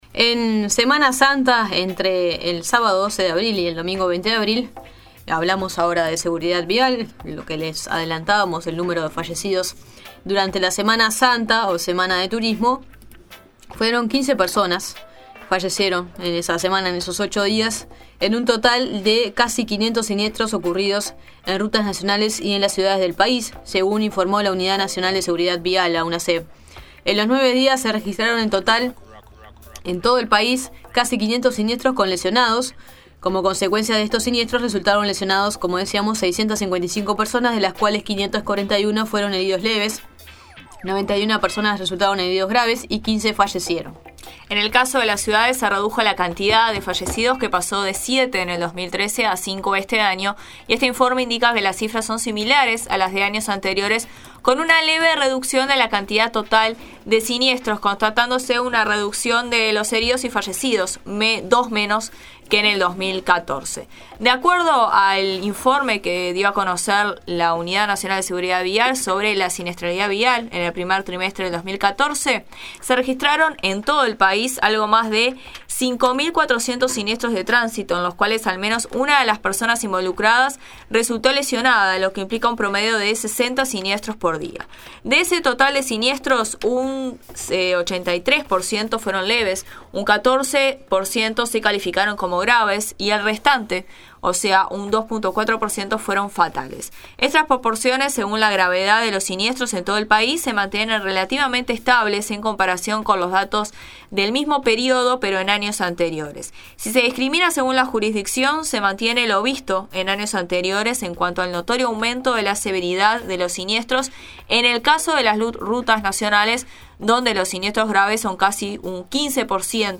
En este sentido, La Nueva Mañana realizó un informe sobre la presentación de este informe a través de la voz de Gerardo Barrios, director de UNASEV y de Hugo Bosca, Director de Tránsito de la Intendencia de Montevideo.